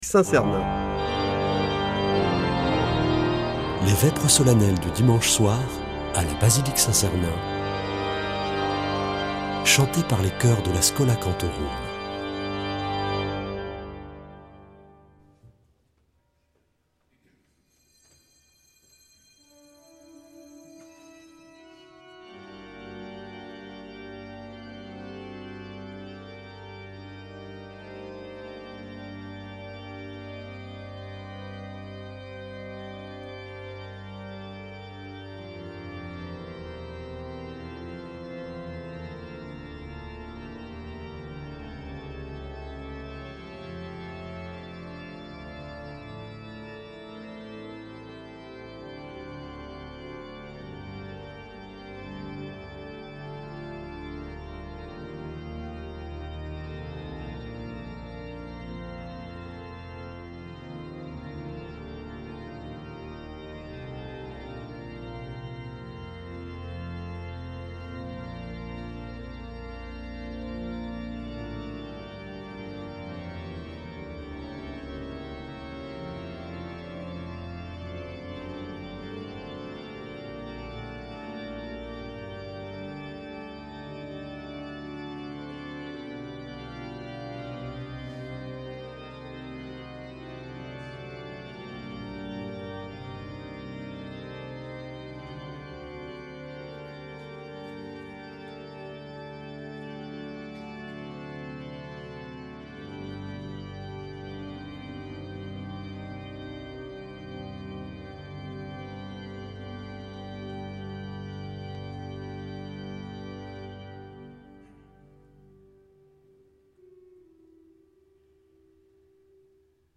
Vêpres de Saint Sernin du 24 nov.
Une émission présentée par Schola Saint Sernin Chanteurs